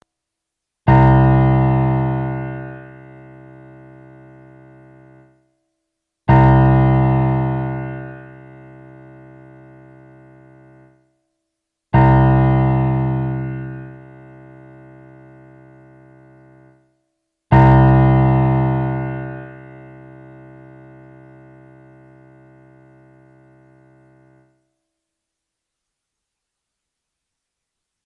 Wenn Sie auf die folgenden Links klicken, hören Sie, wie die Saiten klingen und können ihre Violoncello danach stimmen:
C-Saite (mp3):
cello_c.mp3